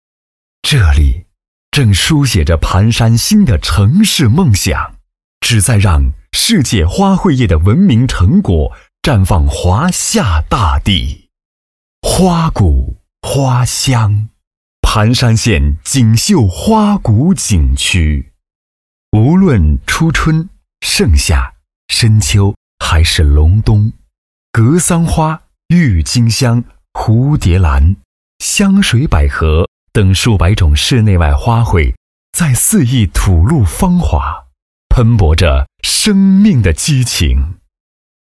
旅游生态男26号
深情缓慢 旅游风光
大气震撼男中音，擅长基情专题，宣传片，讲述旁白题材。